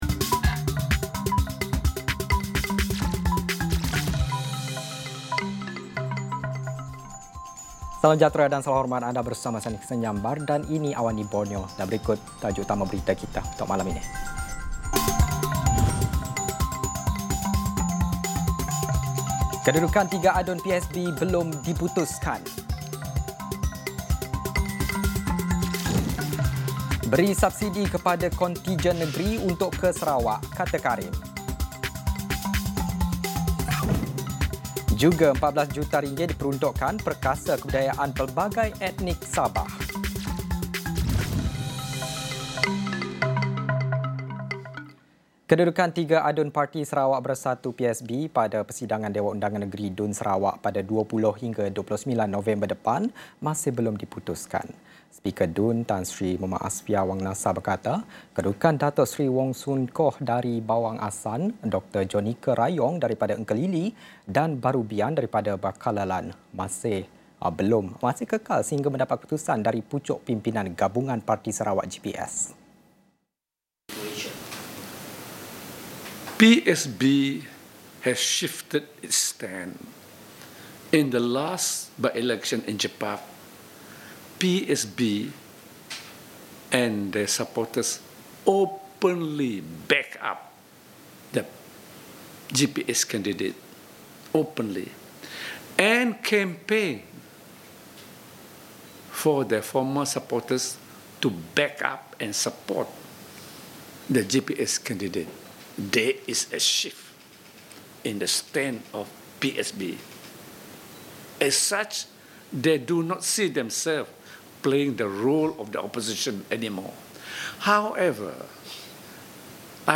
Laporan berita padat dan ringkas dari Borneo